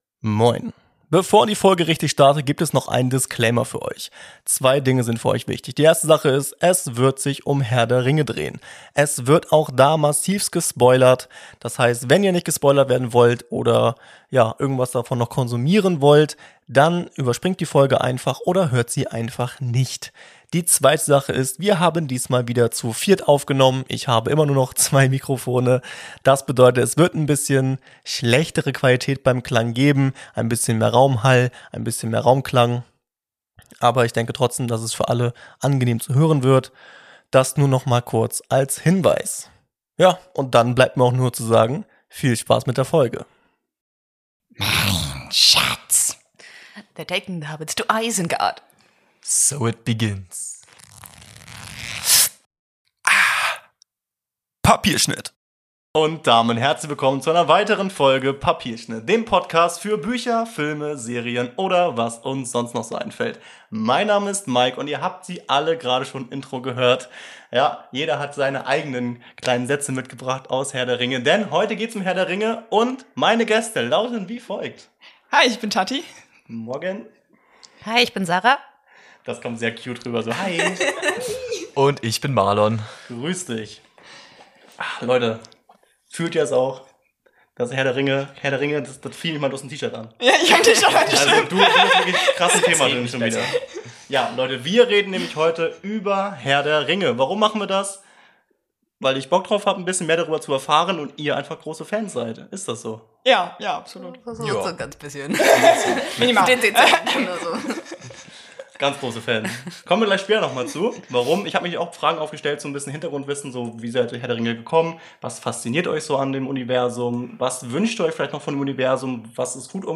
Wir stürzen uns heute zu viert in das wahrscheinlich größte Schlachten Epos von Mittelerde. Wir reden über alles, was rundum das Thema Herr der Ringe geht. Seien es Bücher, Filme, Serien, Spiele etc. Ich spreche mit großen Fans und erfahre, was so besonders an dem ganzen ist.